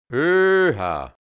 Mundart-Wörter | Mundart-Lexikon | hianzisch-deutsch | Redewendungen | Dialekt | Burgenland | Mundart-Suche: O Seite: 9
öhha Ausruf um Zugtiere anzuhalten